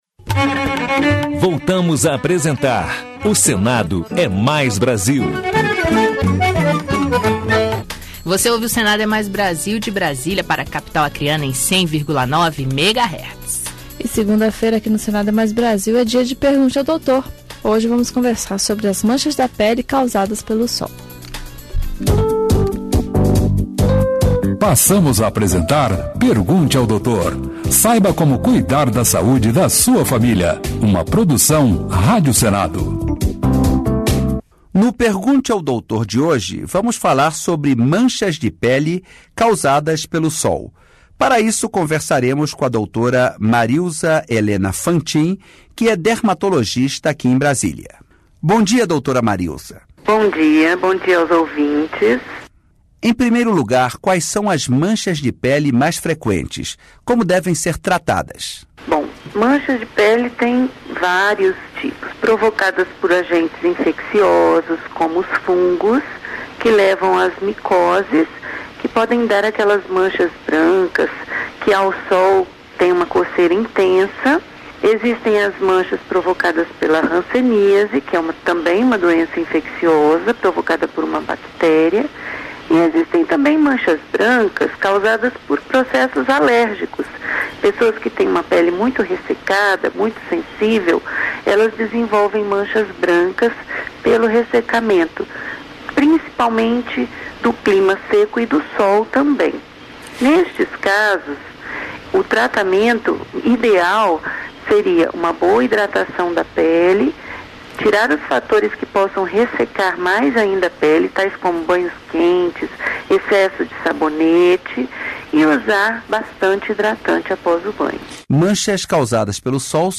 O Senado é Mais Brasil - 3º bloco Pergunte ao Doutor: Manchas na pela causadas pelo sol. Entrevista